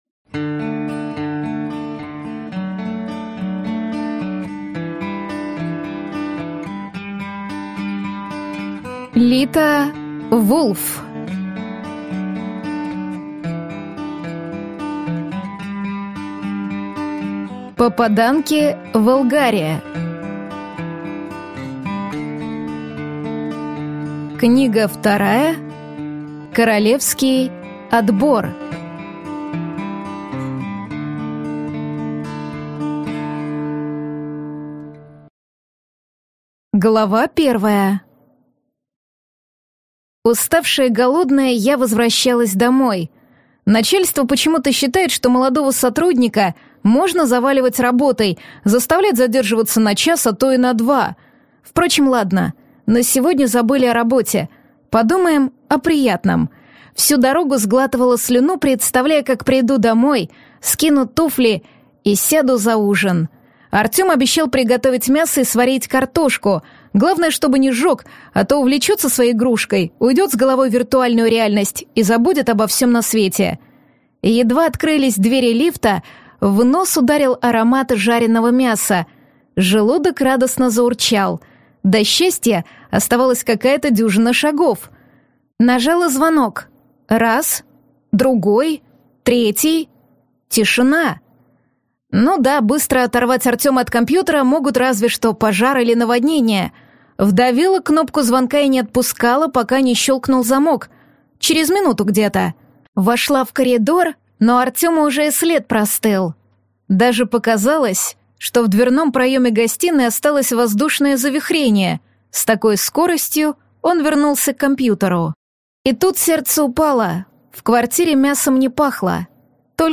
Аудиокнига Королевский отбор | Библиотека аудиокниг